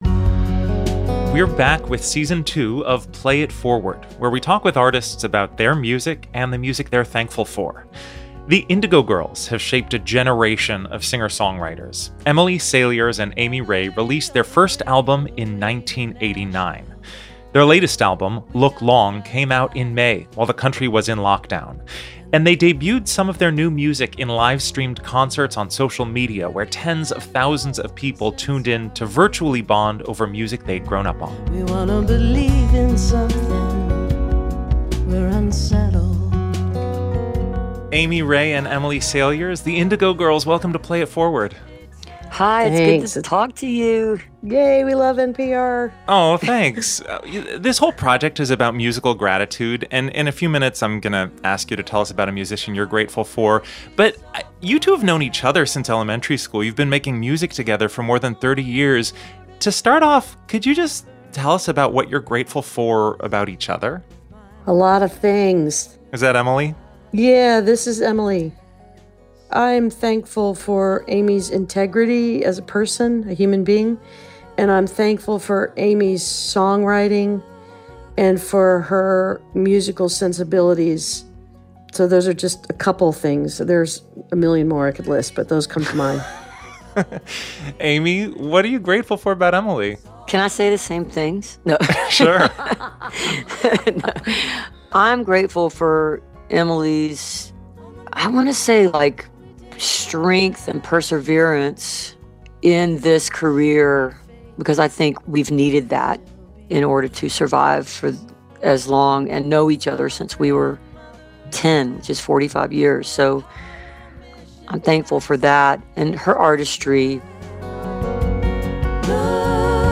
(captured from facebook)
interview